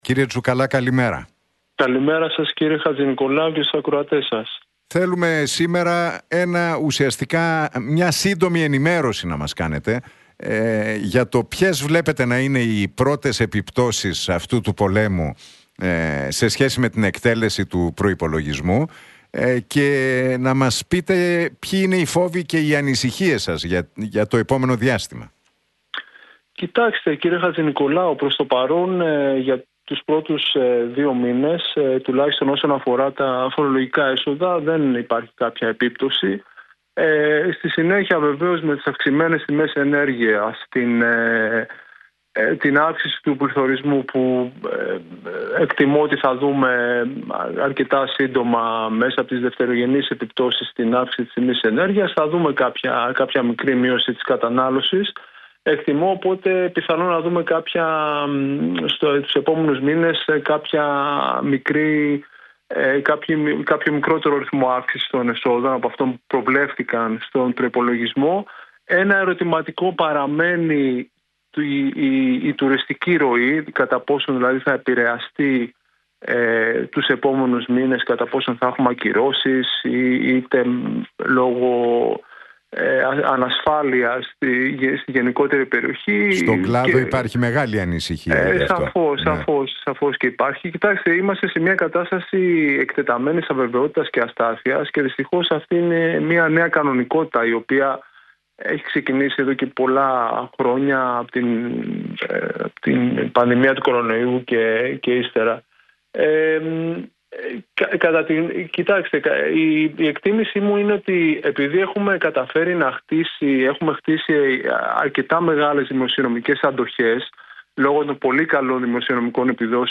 Για την οικονομικές επιπτώσεις του πολέμου στη Μέση Ανατολή μίλησε ο Γιάννης Τσουκαλάς, επικεφαλής του Γραφείου Προϋπολογισμού του Κράτους στη Βουλή, στην εκπομπή του Νίκου Χατζηνικολάου στον Realfm 97,8.